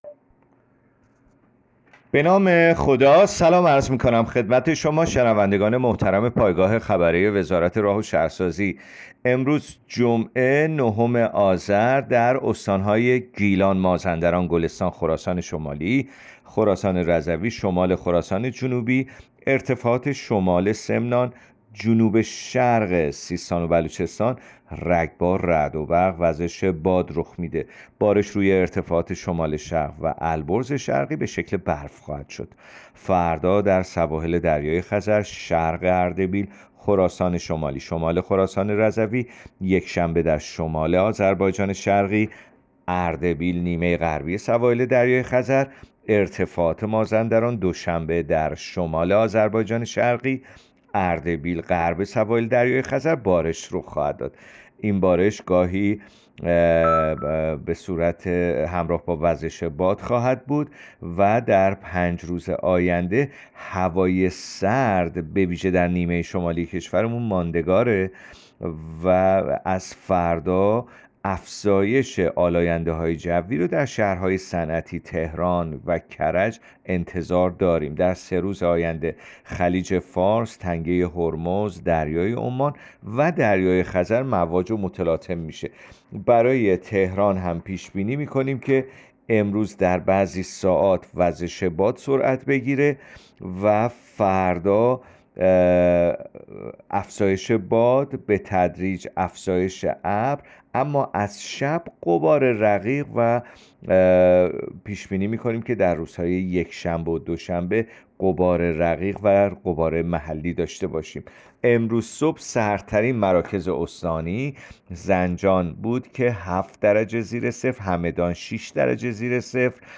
گزارش رادیو اینترنتی پایگاه‌ خبری از آخرین وضعیت آب‌وهوای ۹ آذر؛